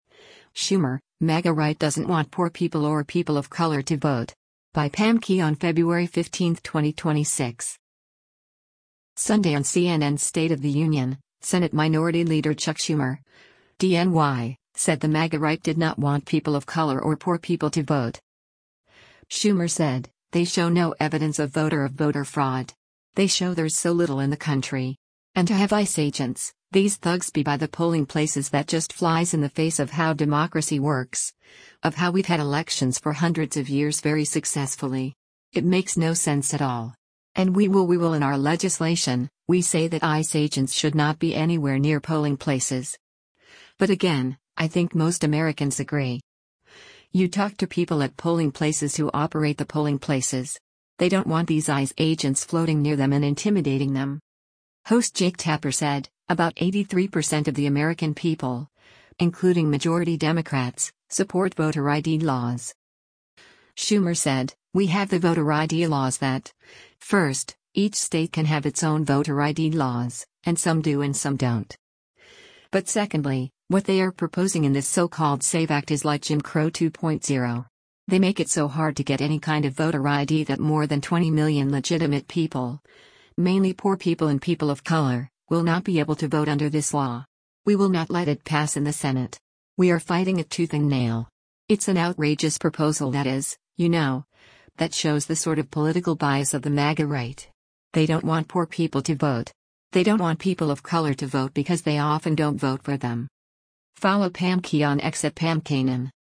Sunday on CNN’s “State of the Union,” Senate Minority Leader Chuck Schumer (D-NY) said the “MAGA right” did not want people of color or poor people to vote.